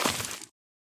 PixelPerfectionCE/assets/minecraft/sounds/item/hoe/till3.ogg at 0cc5b581cc6f975ae1bce078afd85fe00e0d032f
till3.ogg